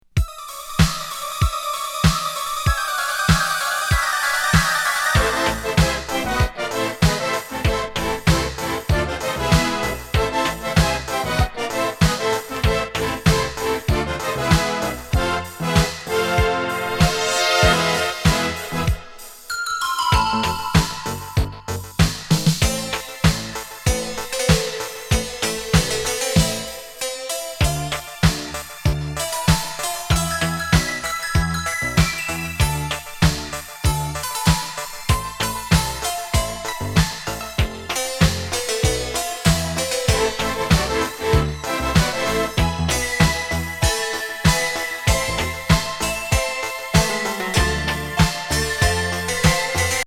演歌+グッドタイミー・ムード・エレクトロ・ビート